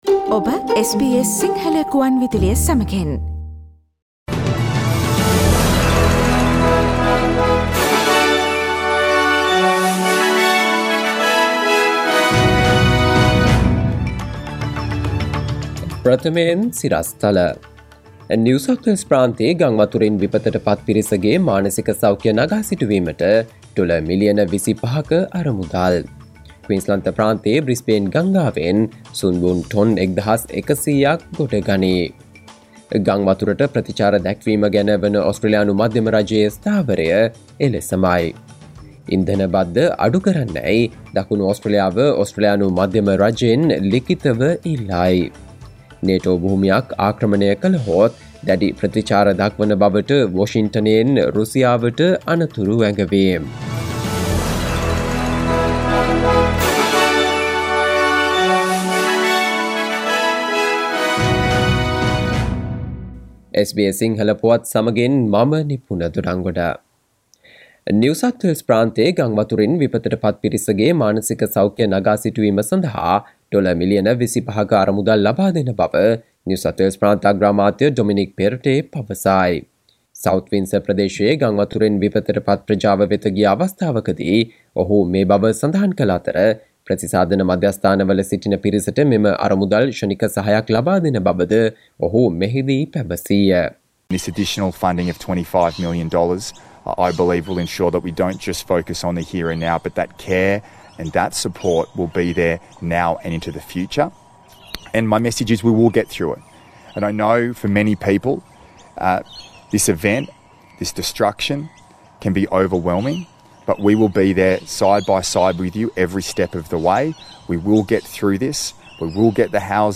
සවන්දෙන්න 2022 මාර්තු 14 වන සඳුදා SBS සිංහල ගුවන්විදුලියේ ප්‍රවෘත්ති ප්‍රකාශයට...